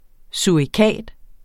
Udtale [ suɐ̯iˈkæˀd ]